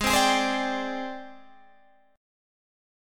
Listen to G#M7b5 strummed